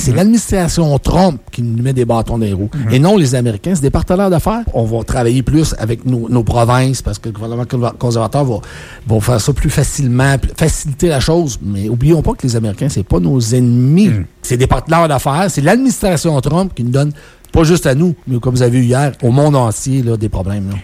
En entrevue à Radio Beauce